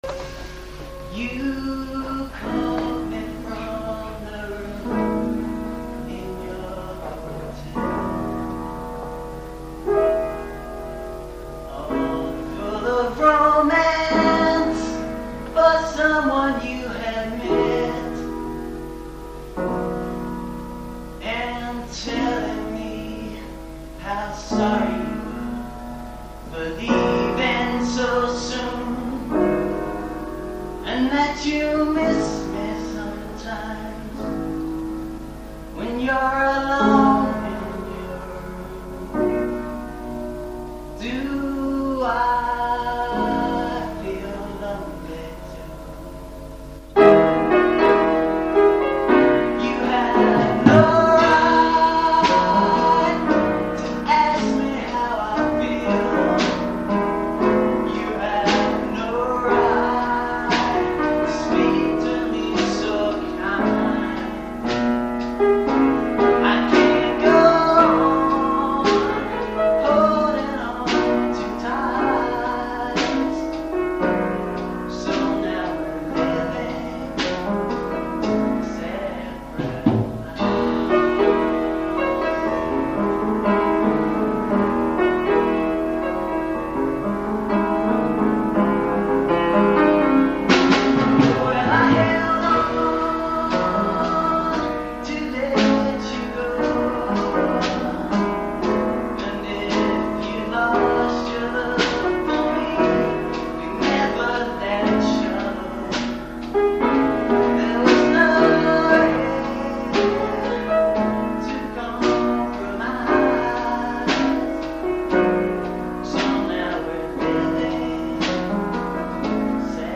In these recordings, notice that there are never more than 3 instruments (including voice), cos the rules limited us to 3 people max.
• Piano
• Drums - [aaargh, forgot his name!!
Competition Version (mp3) (incomplete - tape ran out...)